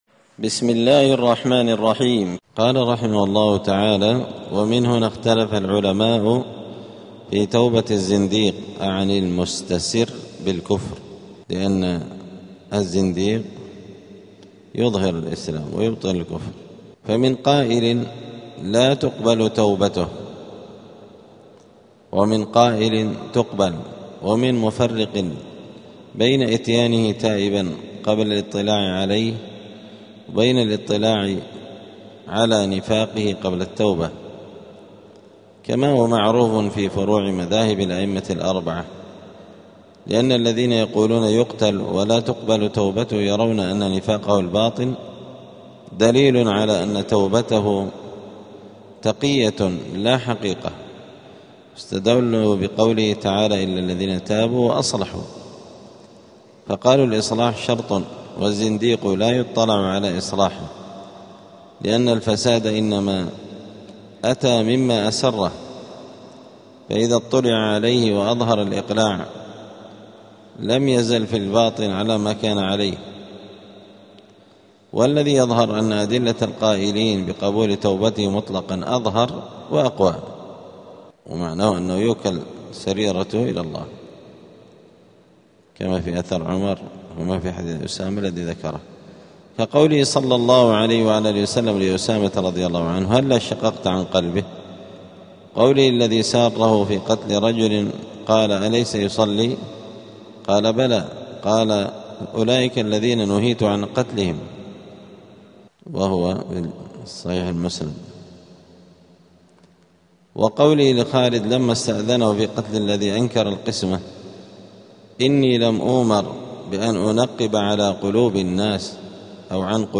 *الدرس الثامن عشر (18) {سورة آل عمران}.*